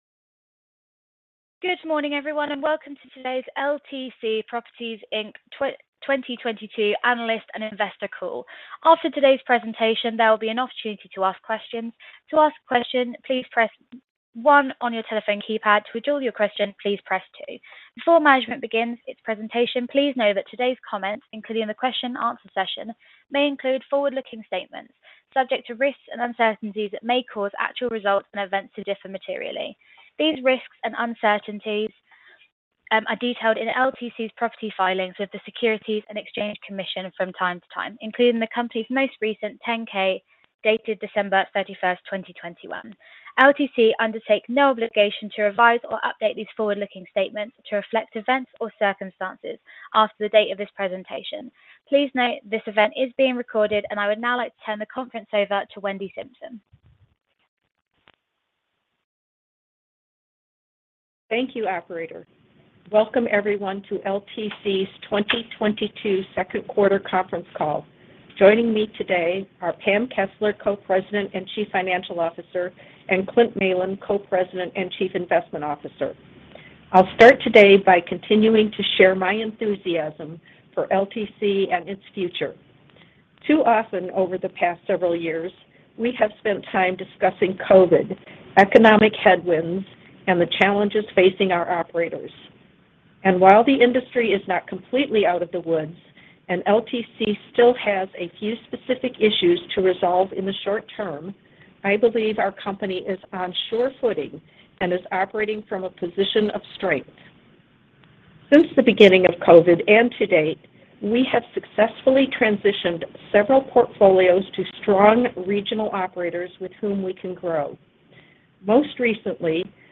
Earnings Webcast Q2 2022 Audio